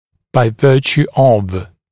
[baɪ ‘vɜːʧuː ɔv] [-tju-][бай ‘вё:чу: ов] [-тйу-]в силу